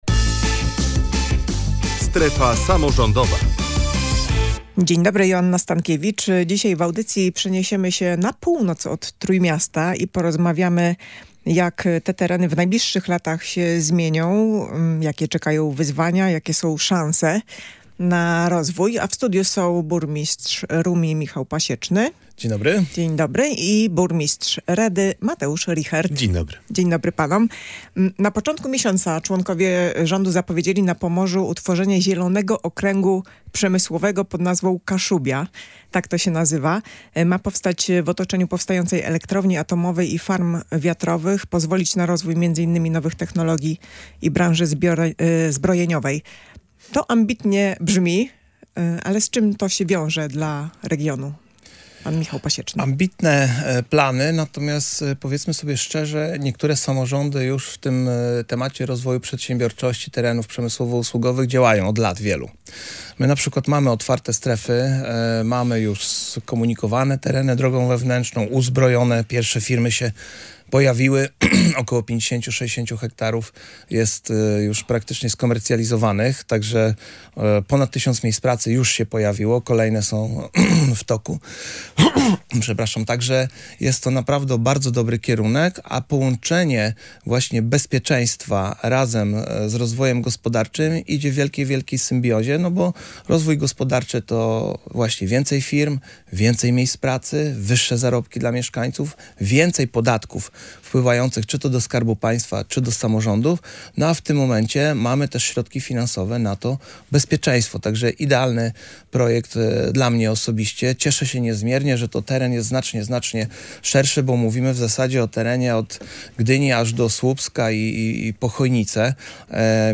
Burmistrz Rumi Michał Pasieczny oraz burmistrz Redy Mateusz Richert byli gośćmi audycji „Strefa Samorządowa”.